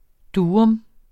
Udtale [ ˈduːɔm ]